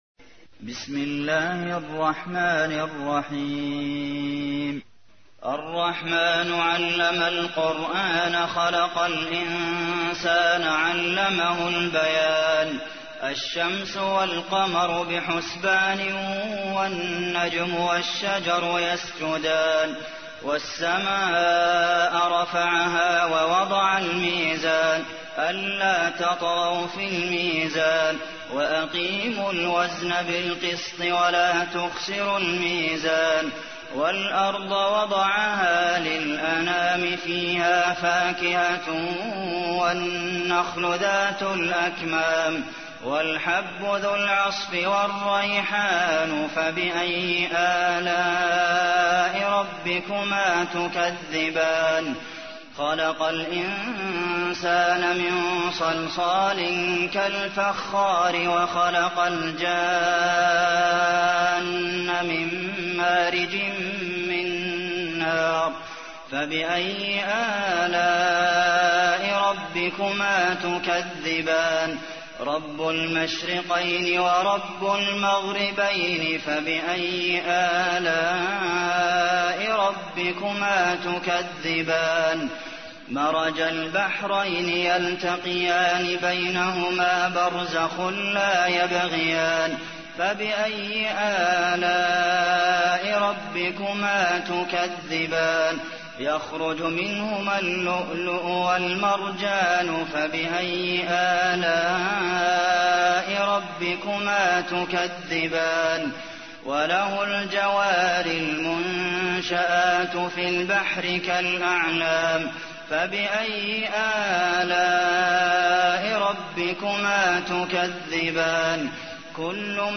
تحميل : 55. سورة الرحمن / القارئ عبد المحسن قاسم / القرآن الكريم / موقع يا حسين